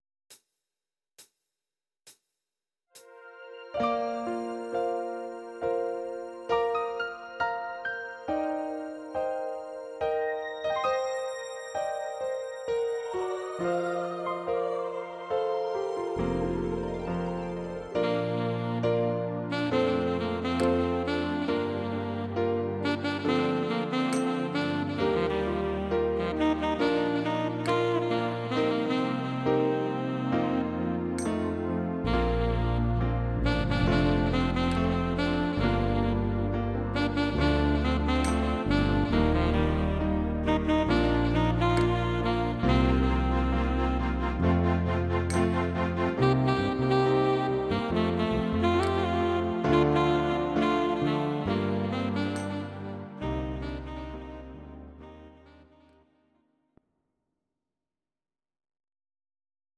Audio Recordings based on Midi-files
Pop, Musical/Film/TV, 2000s